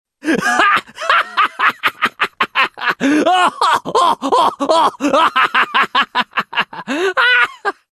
Risada Wukong (LoL)
Risada do macaco rei Wukong, de League Of Legends (LoL).
risada-wukong-lol.mp3